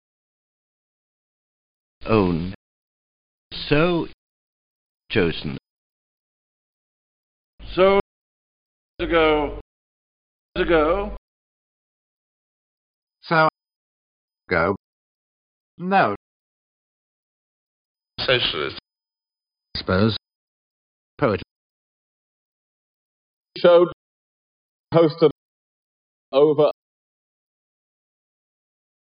Example 6: The RP GOAT vowel [əʊ]-[əo]
Three examples each by the five speakers, in the same order as Example 4: